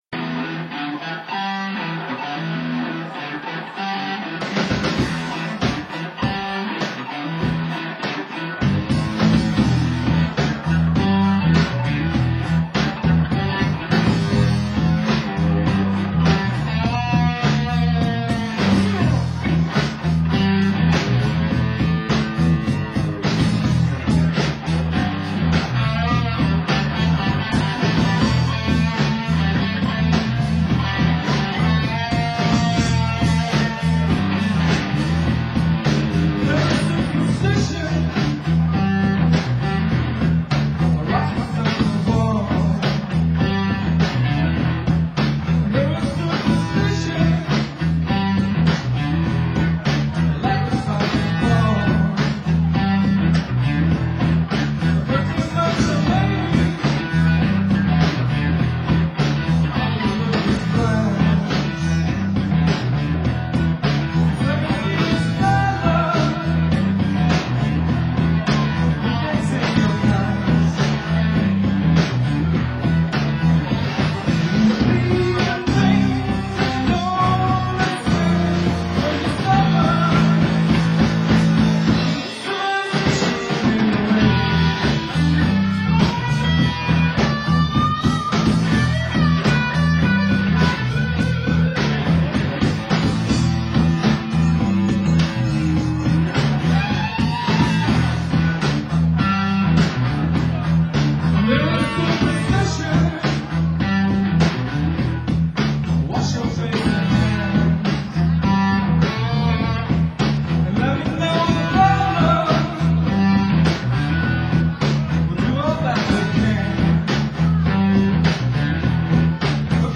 drums
electric guitar
bass